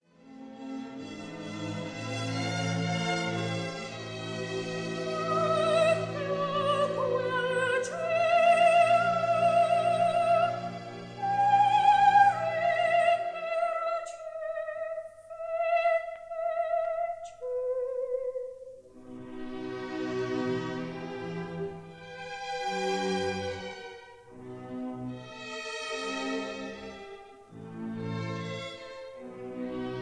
Recorded in Paris on 12 October 1955